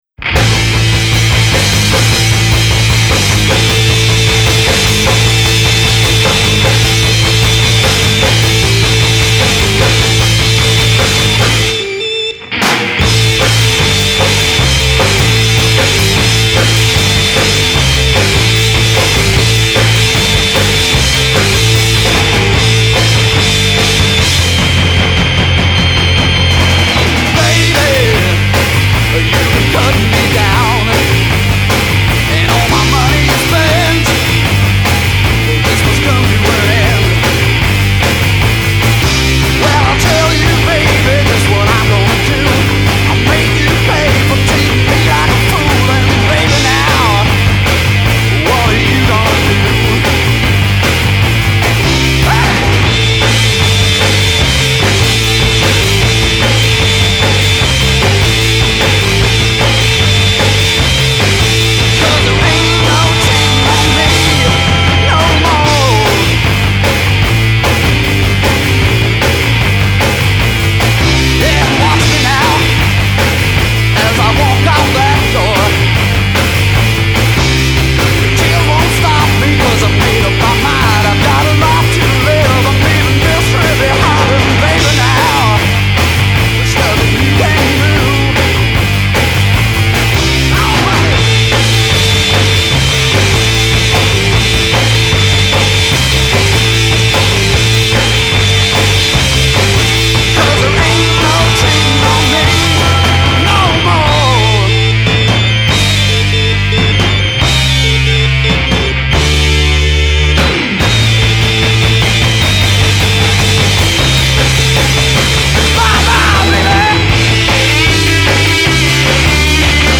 Bass.
Vocals, Guitar.
Farfisa , Vocals.
Drums.